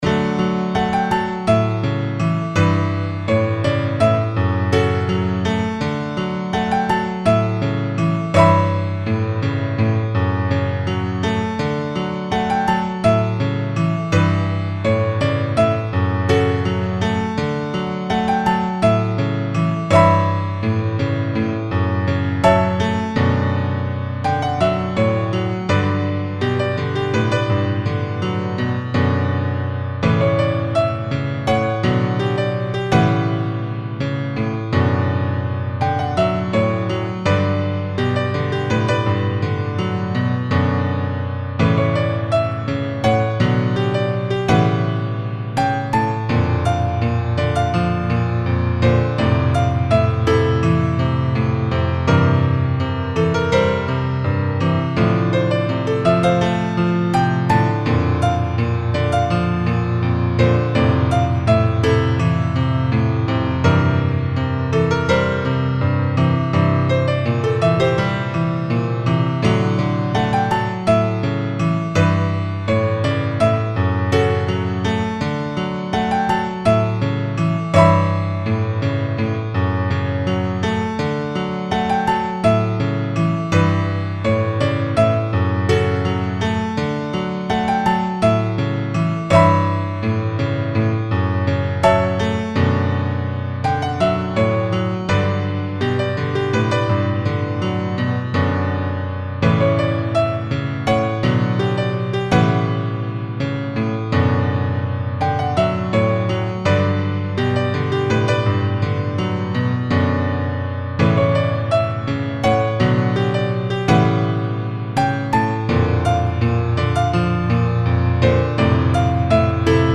音楽ジャンル： アコースティック
楽曲の曲調： SOFT